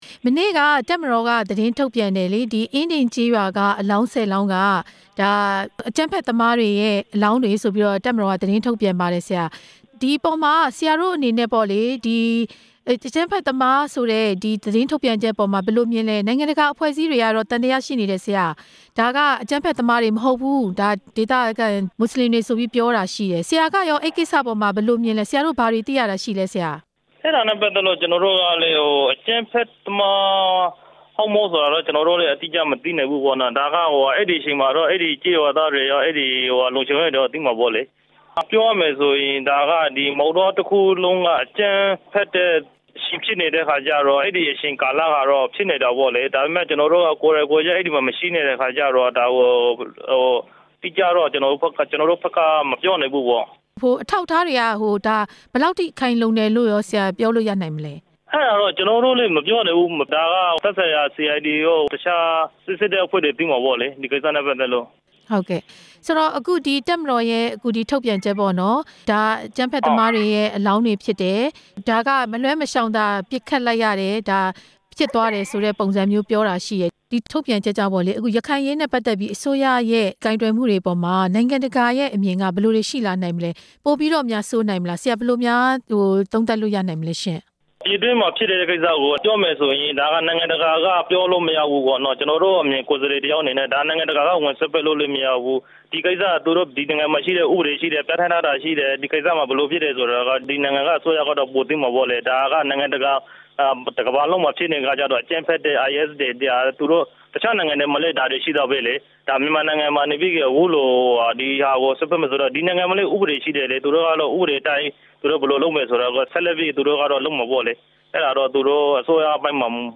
အင်းဒင်ရွာ သတ်ဖြတ်မှု ဒေသခံလွှတ်တော်အမတ်နဲ့ မေးမြန်းချက်